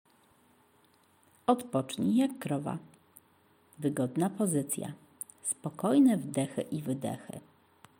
krowa
krowa.mp3